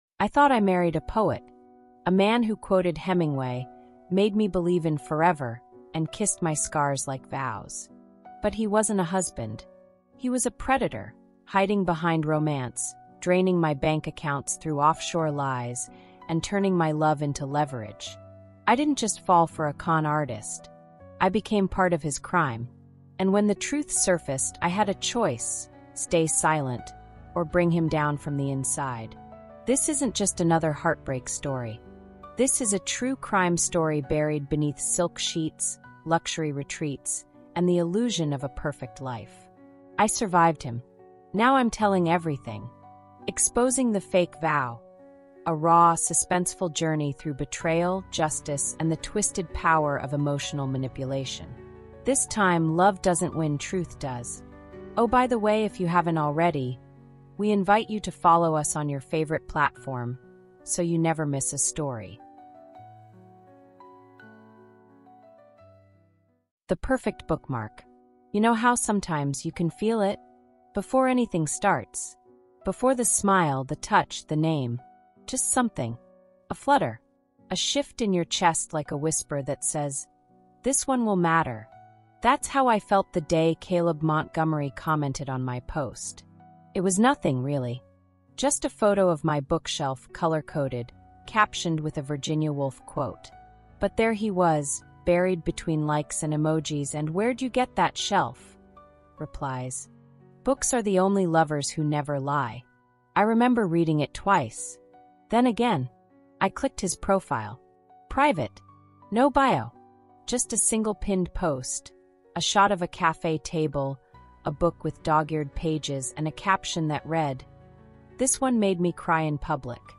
Exposing the Fake Vow Heartbreak & Drama in This Romance Audiobook